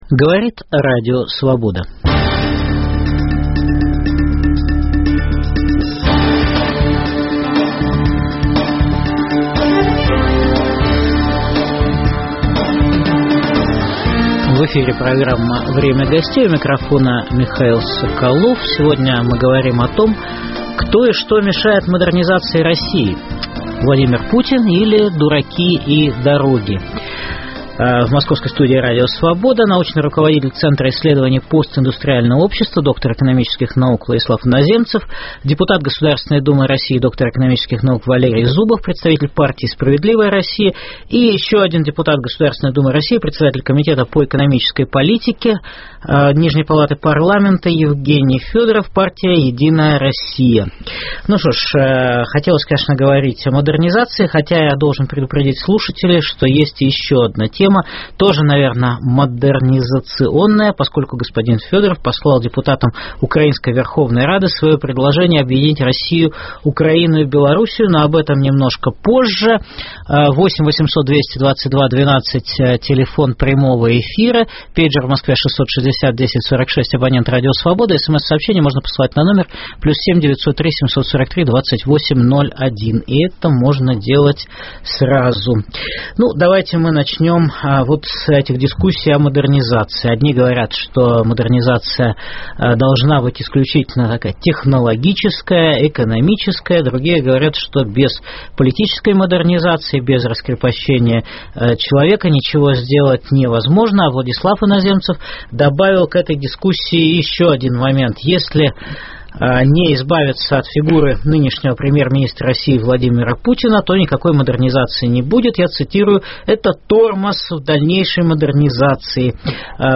Кто и что мешает модернизации России: Путин или "дураки и дороги"? В программе дискутируют: научный руководитель Центра исследований постиндустриального общества Владислав Иноземцев, депутаты Государственной думы России Евгений Федоров ("Единая Россия") и Валерий Зубов ("Справедливая Россия").